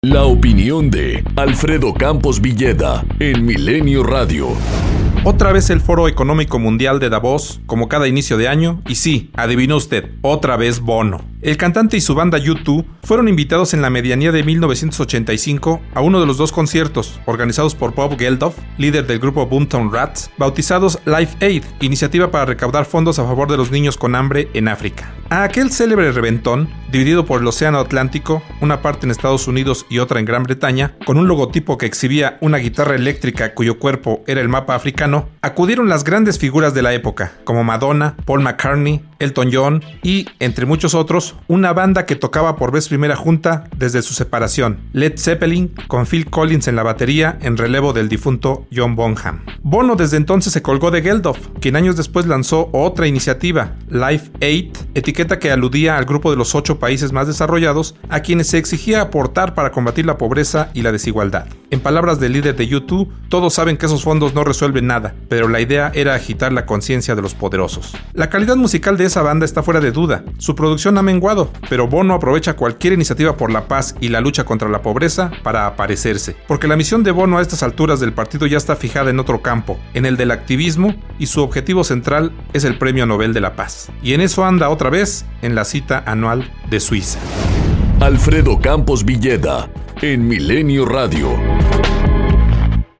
COMENTARIO EDITORIAL 250116